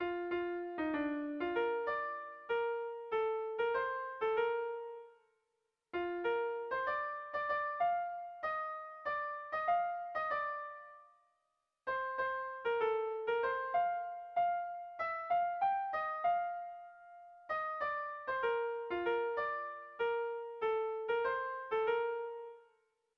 Irrizkoa
Zortziko txikia (hg) / Lau puntuko txikia (ip)
ABDA2